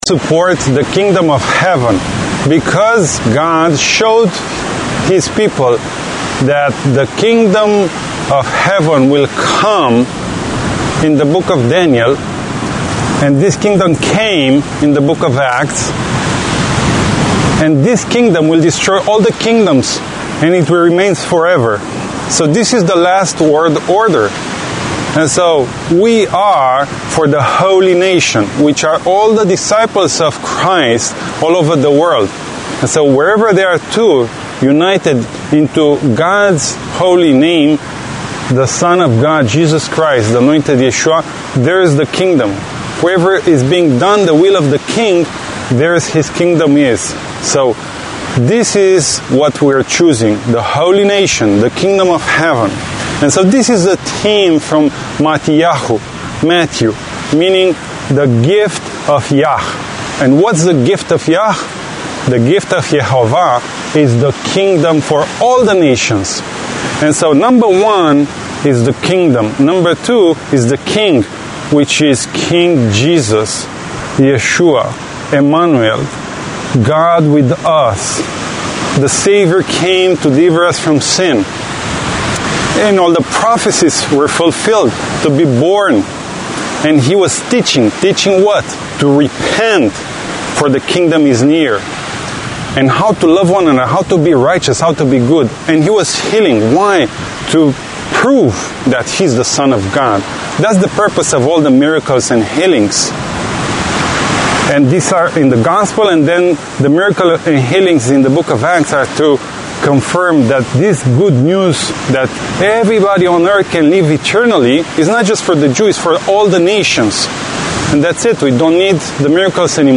Bible Reading Audio Week 48 of 2022 Matthew Explained